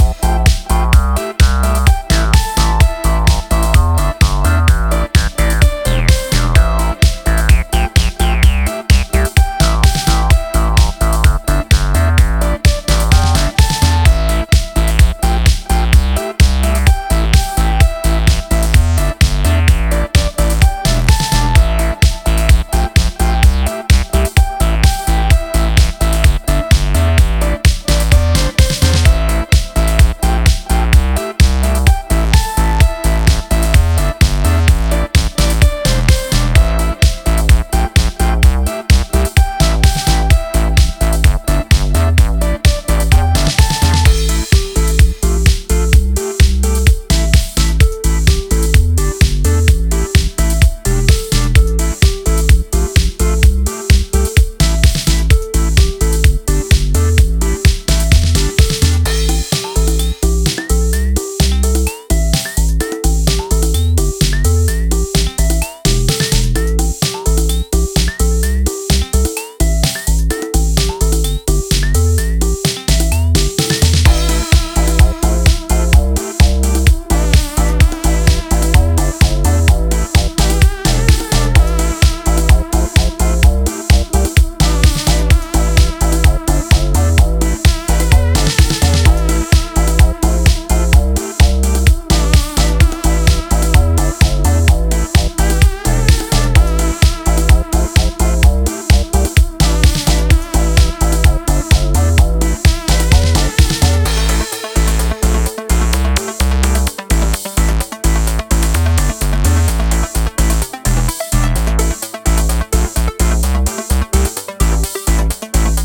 offering a rich blend of electronic sounds